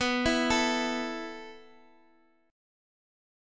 A5/B Chord
A-5th-B-x,x,x,4,5,5-8.m4a